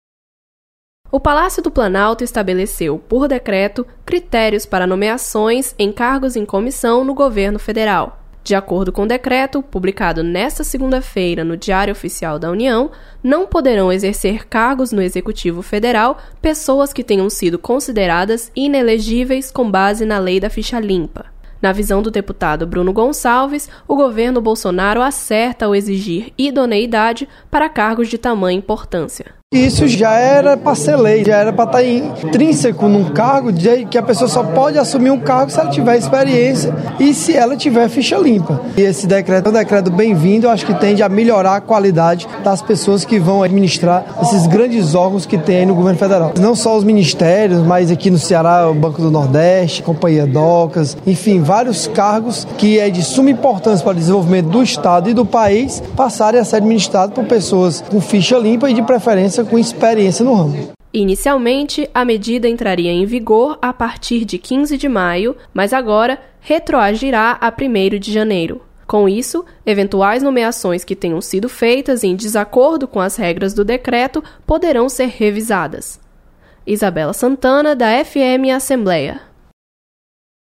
Deputado comenta decreto que estabelece ficha limpa em cargos de confiança do Executivo.